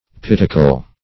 Search Result for " pittacal" : The Collaborative International Dictionary of English v.0.48: Pittacal \Pit"ta*cal\ (p[i^]t"t[.a]*k[a^]l), n. [Gr. pi`tta, pi`ssa, pitch + kalo`s beautiful: cf. F. pittacale.]